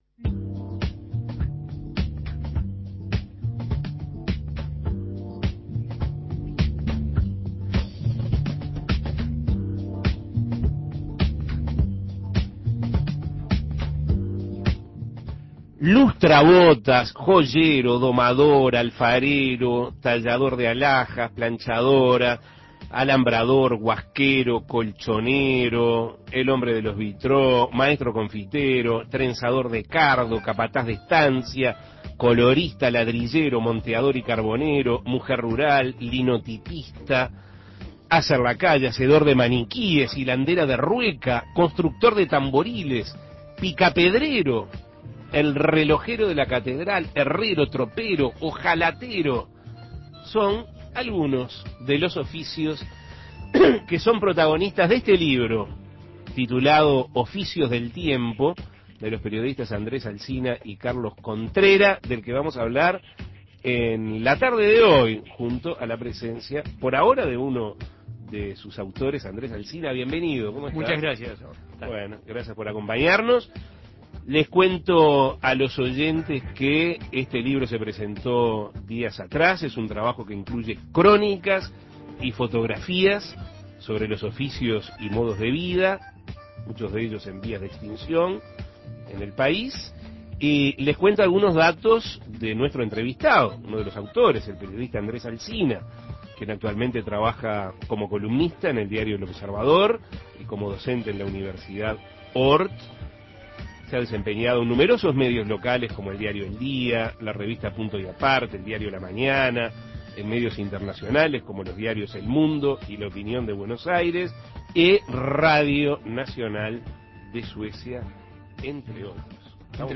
A su vez, el libro incluye crónicas y fotografías sobre los oficios y modos de vida en vías de extinción en Uruguay. Escuche la entrevista.